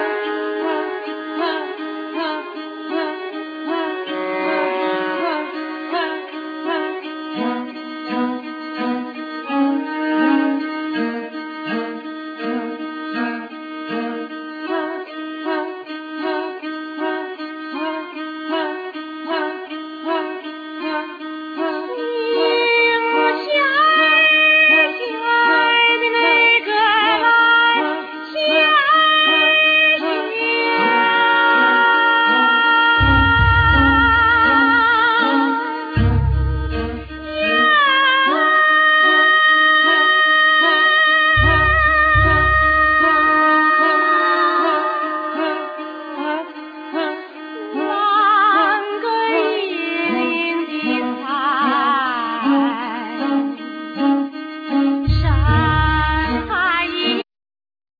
Voclas
Violin,Bariton violin,Viola
Guiatr,Mandlin,Cello,Percussions,Zither,Kobala,Vocals
Piano,Cembalo,Guitar
Percussion,Drums